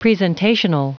Prononciation du mot presentational en anglais (fichier audio)